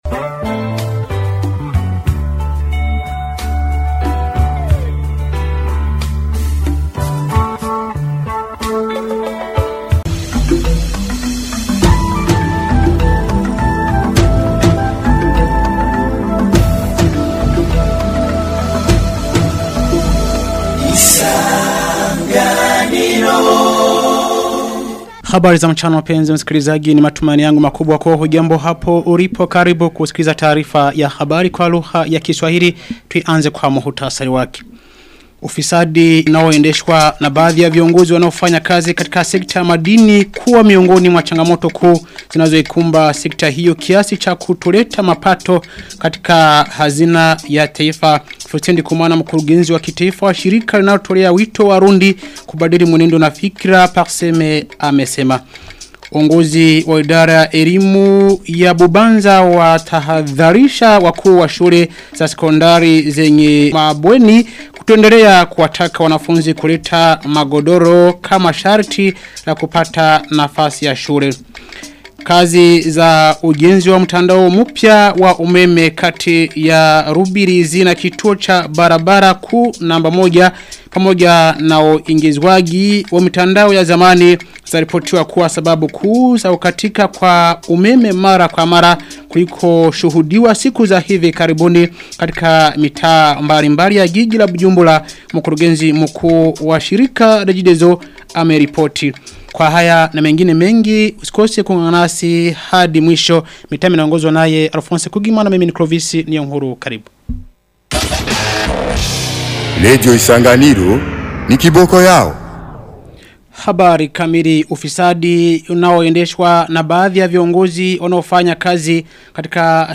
Taarifa ya habari ya tarehe 10 Septemba 2025